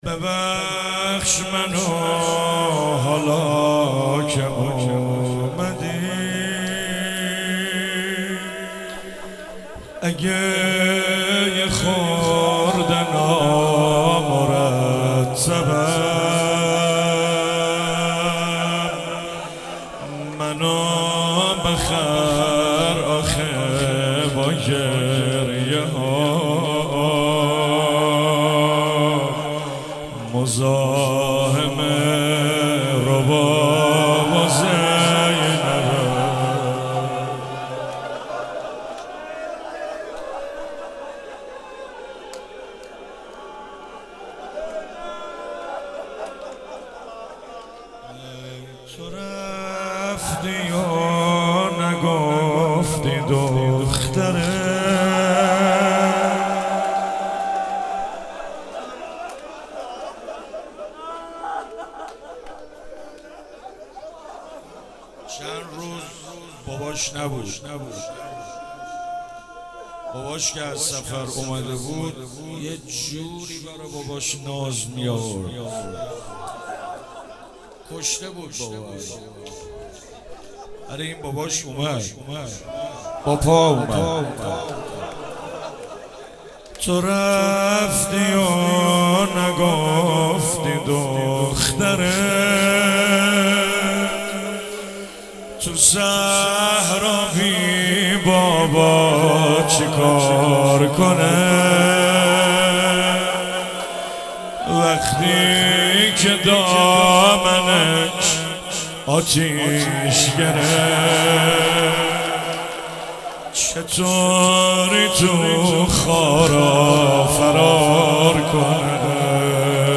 حسینیه حضرت زینب (سلام الله علیها)
روضه حضرت رقیه(س)